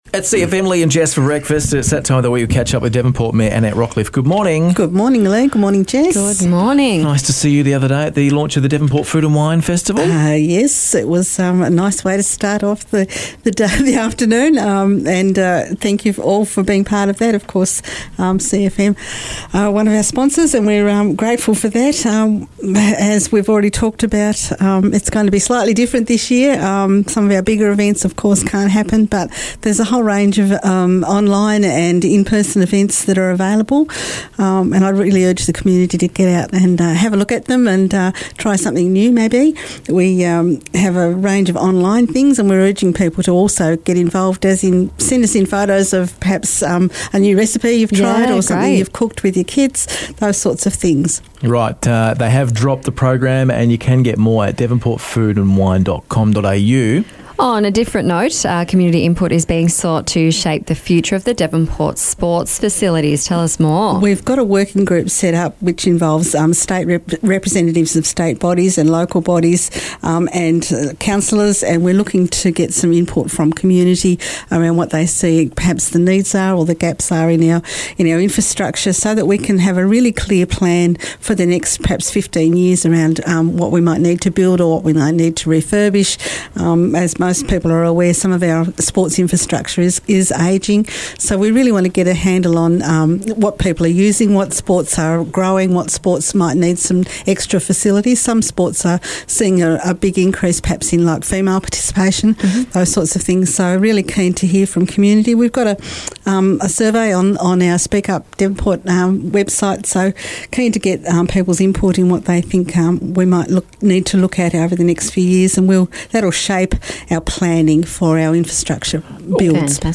Annette Rockliff, Mayor of Devonport, talks through a number of happenings around the city at the moment.